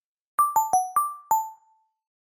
На этой странице собраны звуки электронной почты: уведомления о новых письмах, отправке сообщений и другие сигналы почтовых сервисов.
Красивая трель